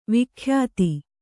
♪ vikhyāti